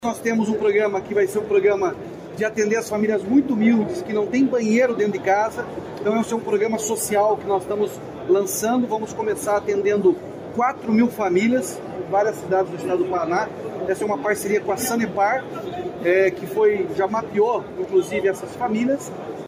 Sonora do governador Ratinho Junior sobre o lançamento do programa Banheiro em Casa